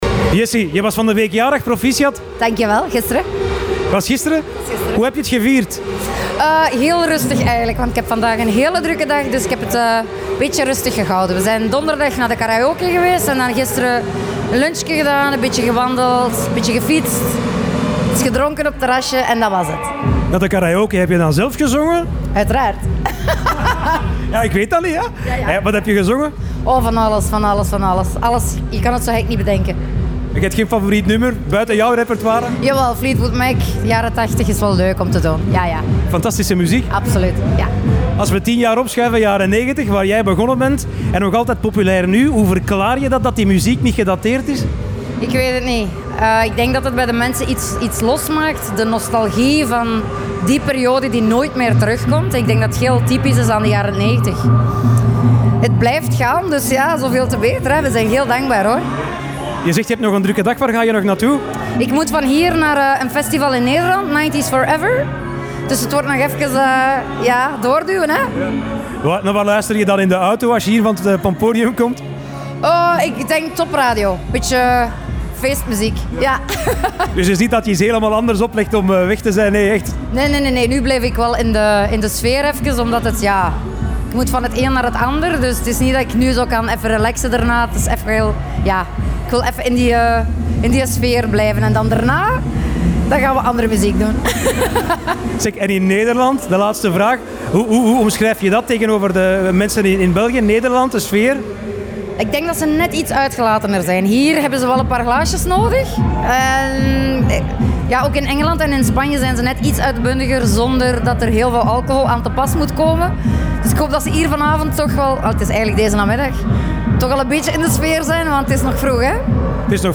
Interviews met artiesten